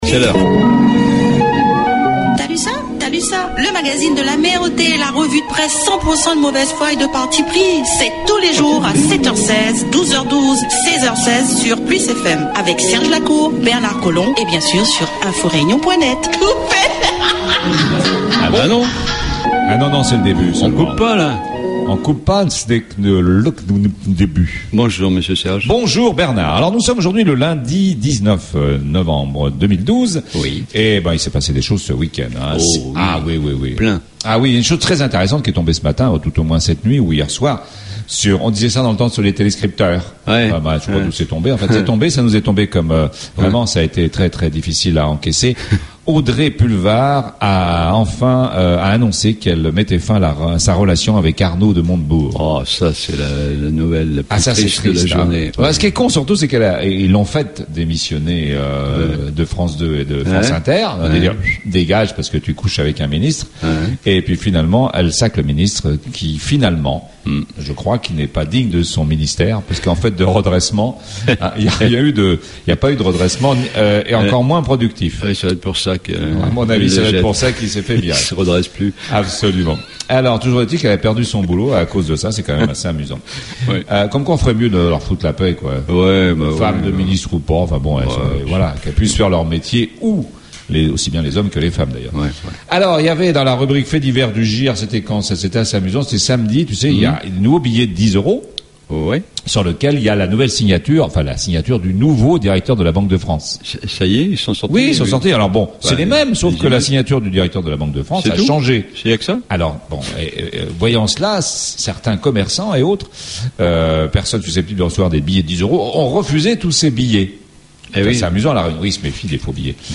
La Revue de Presse politiquement incorrecte la mieux informée, la plus décalée, la plus drôle, la moins sérieuse et la plus écoutée sur PLUS FM 100.6 dans le Nord, et 90.4 dans l'Ouest...